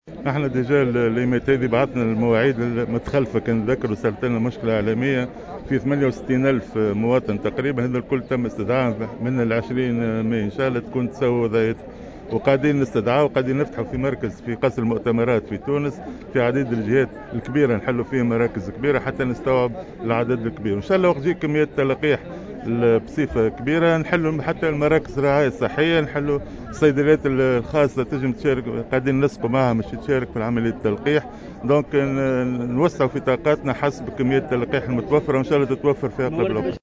وبيّن المهدي في تصريح لمراسل الجوهرة أف أم خلال زيارته إلى ولاية صفاقس اليوم، أنّه وبعد وصول كميات إضافية من التلاقيح، سيقع فتح مراكز الرعاية الأساسية، ويتم حاليا التنسيق مع الصيدليات الخاصة لتمكينها من إجراء عمليات التطعيم.